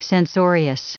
Prononciation du mot censorious en anglais (fichier audio)
Prononciation du mot : censorious